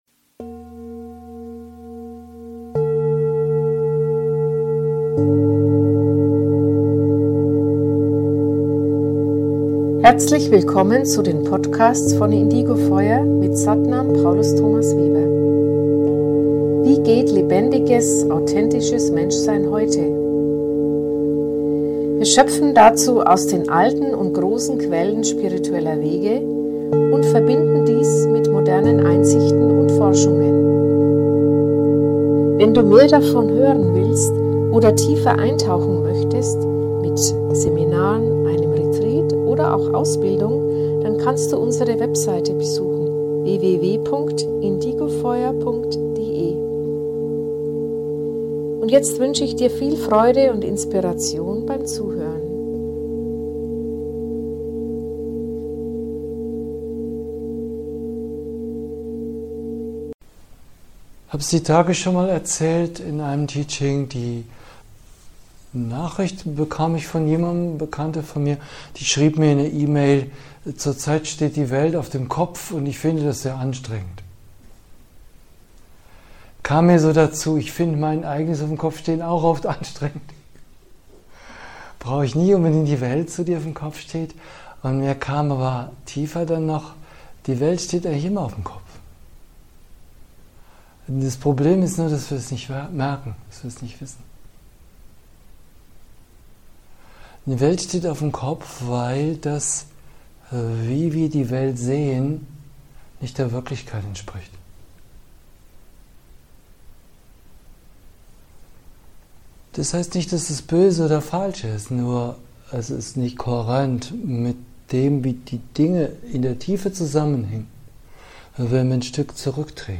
Es öffnet unser menschliches Herz mit seiner Intelligenz eines neuen Sehens, das Geist und Herz und unser ganzes Menschsein zusammenführt. - Ein Live-Mitschnitt aus einem "Herz-Retreat" von uns.